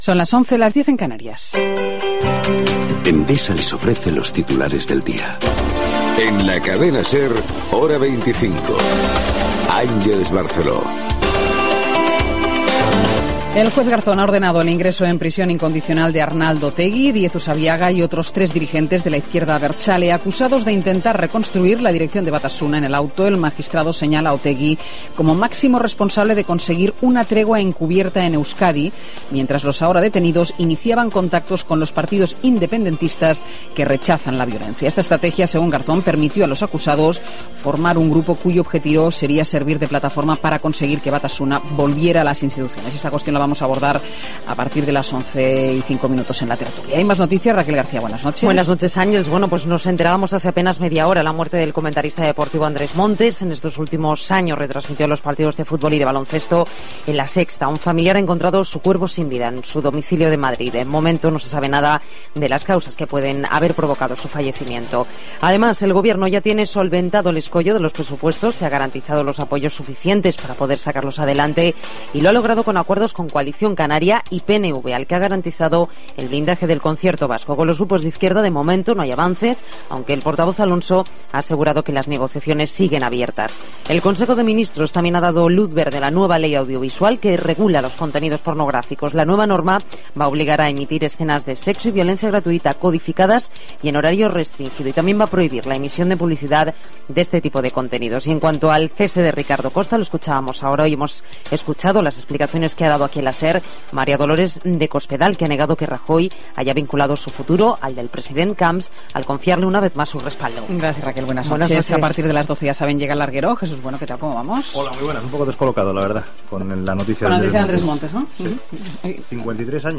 Hora, publicitat, careta, presó per a diriguents de l'esquerra abertzale basca, mort del comentarista esportiu Andrés Montes, pressupostos de l'Estat, etc. Esports, el temps
Informatiu